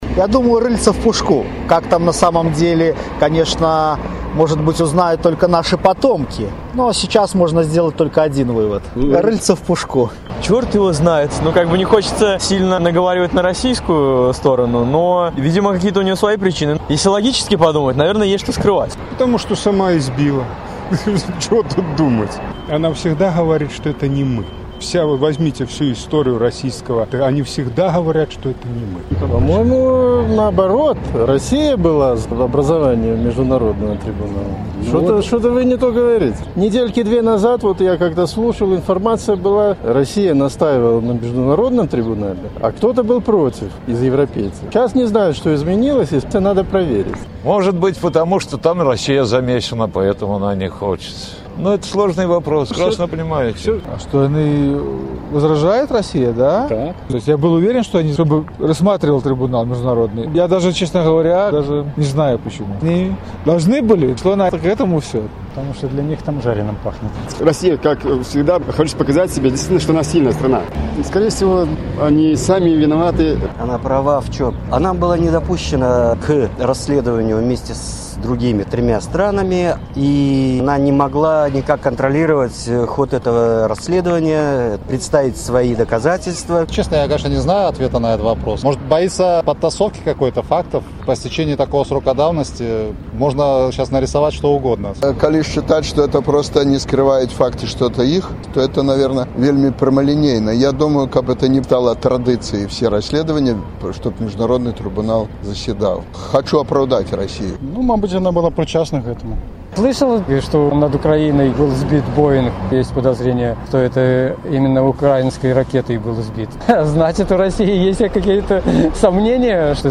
«Расеі ёсьць што ўтойваць», — апытаньне ў Магілёве
Vox populi
На пытаньне Свабоды «Як вы лічыце, чаму Расея выступіла супраць стварэньня міжнароднага трыбуналу па расьсьледаваньні зьбіцьця лайнэра над Данбасам?» адказвалі жыхары Магілёва.